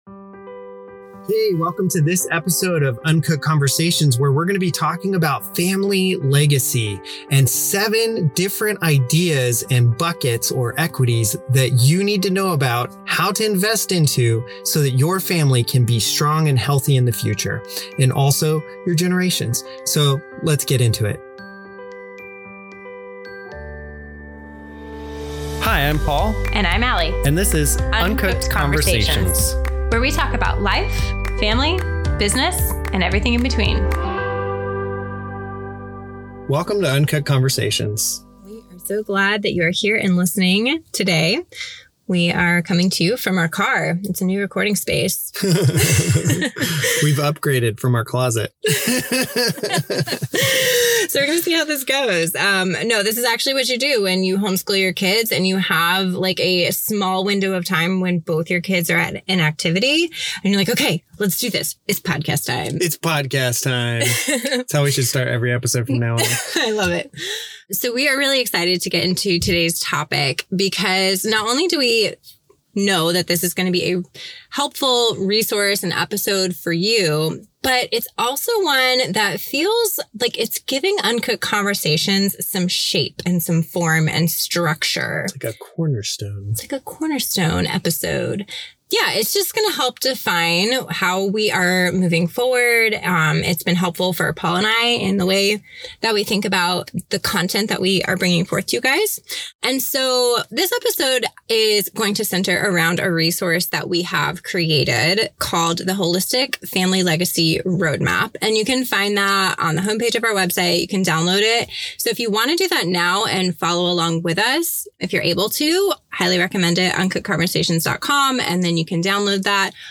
We are playful, authentic and honest with a good helping of deep philosophical thinking.